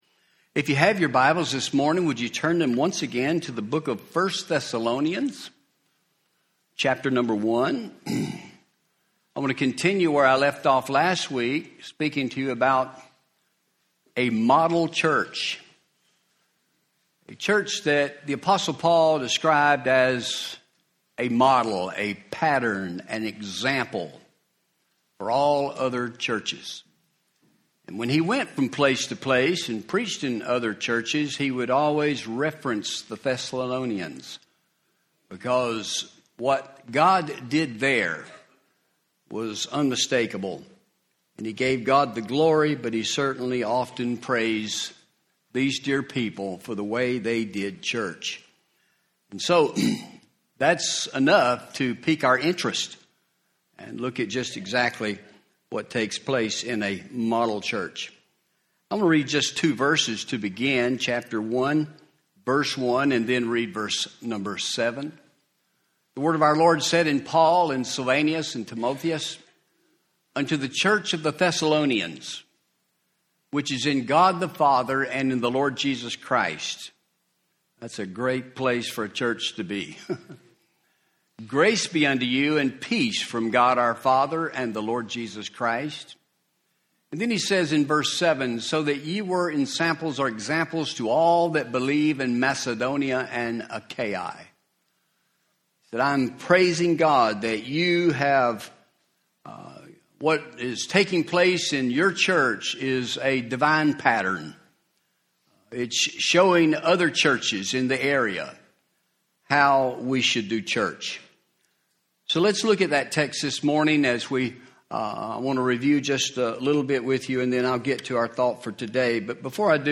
Home › Sermons › A Model Church